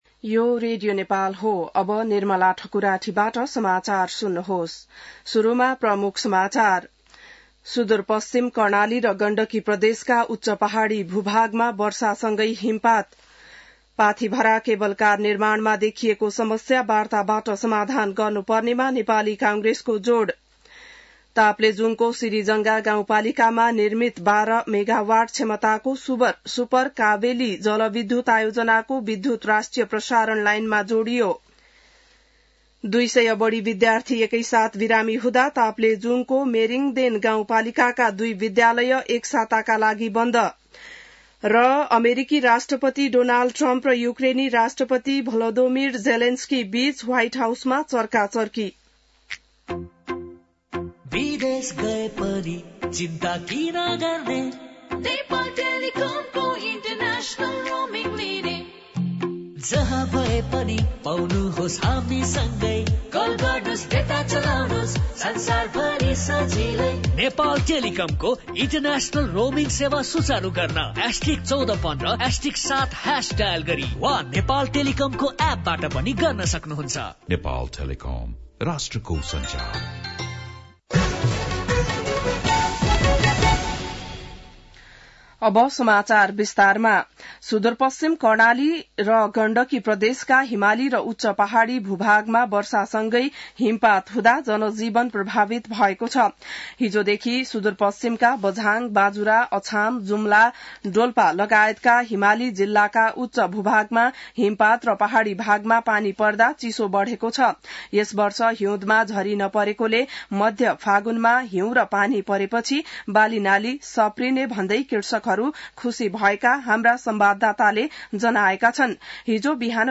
बिहान ७ बजेको नेपाली समाचार : १८ फागुन , २०८१